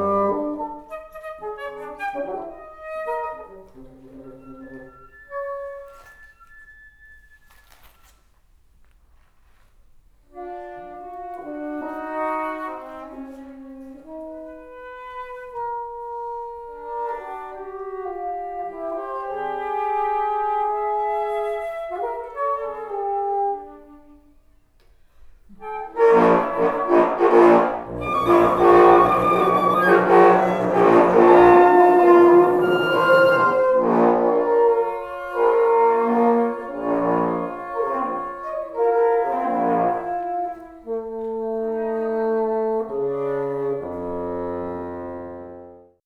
Trio pour flûte basson et cor
Trio for flute, bassoon and horn 5 minutes2012